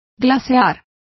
Complete with pronunciation of the translation of iced.